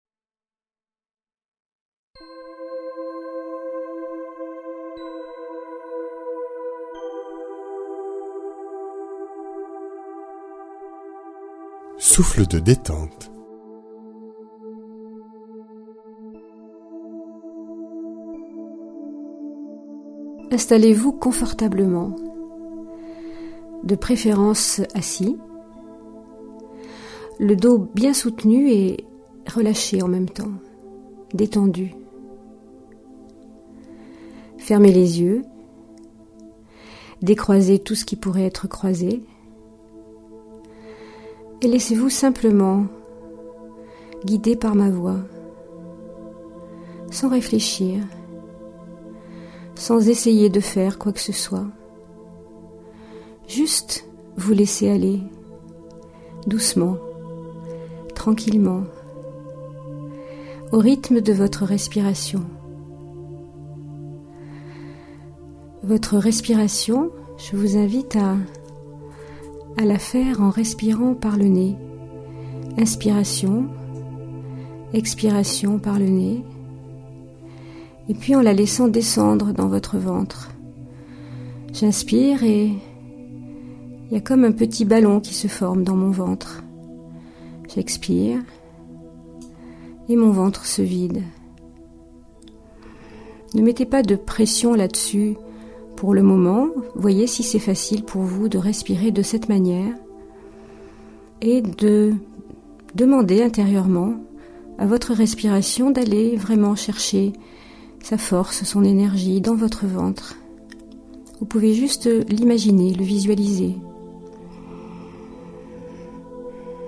Relaxations et visualisations guidées